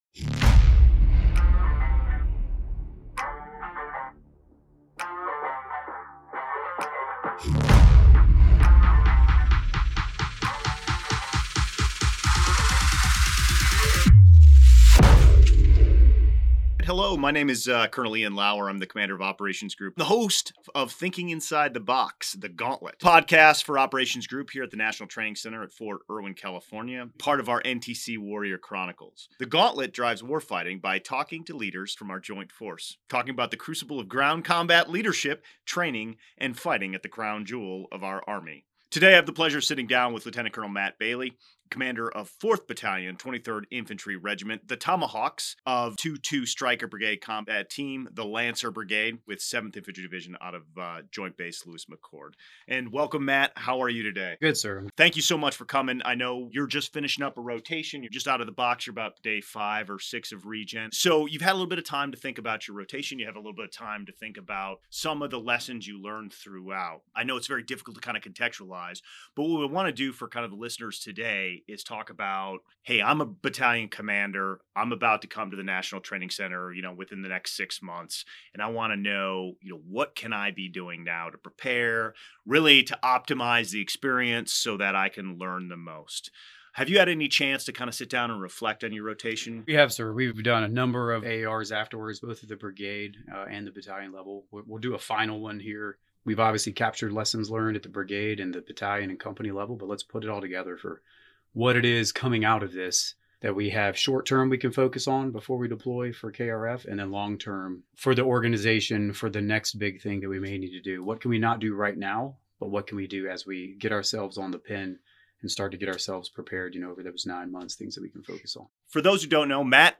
Thinking Inside the Box – The Gauntlet, part of the NTC Warrior Chronicles, brings you interviews with the United States Army’s experts in combined arms maneuver, the Observer Coach Trainers (OC/Ts) of Operations Group, at the National Training Center (NTC), Fort Irwin, California.